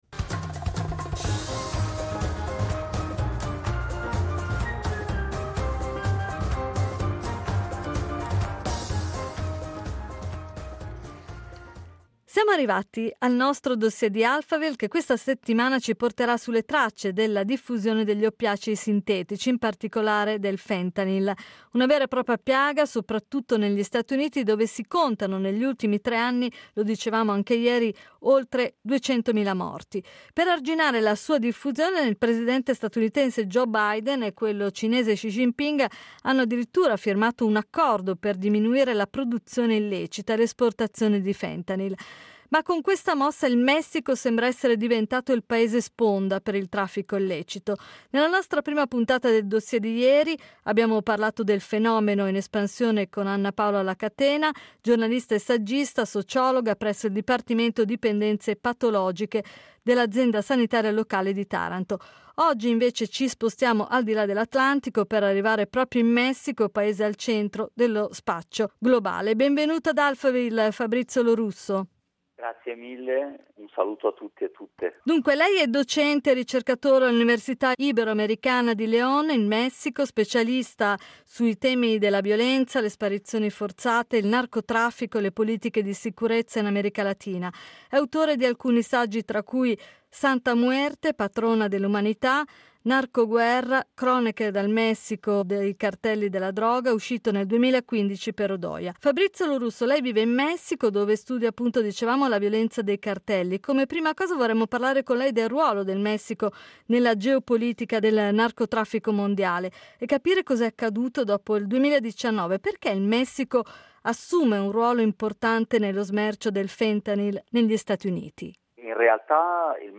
Tutto quello che muove il fentanyl: intervista